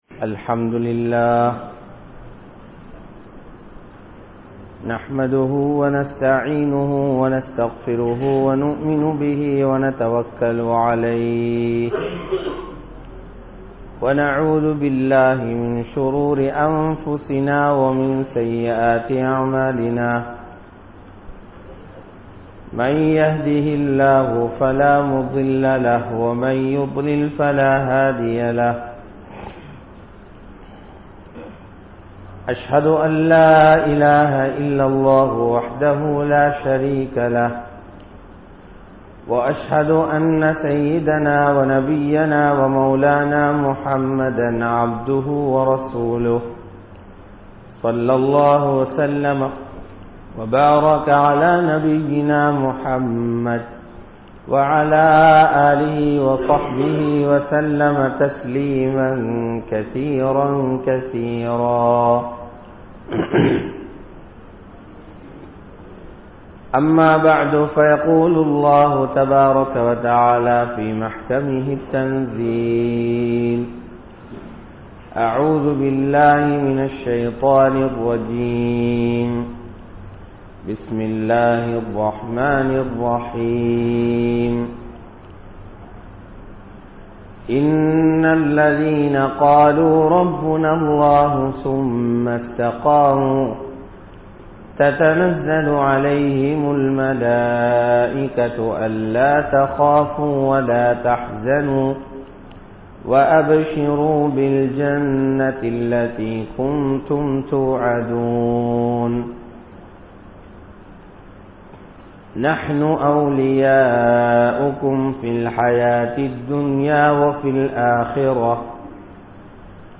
Dheenil Urthiyaaha Irungal (தீணில் உறுதியாக இருங்கள்) | Audio Bayans | All Ceylon Muslim Youth Community | Addalaichenai
Kurunegala, Aswedduma Jumua Masjidh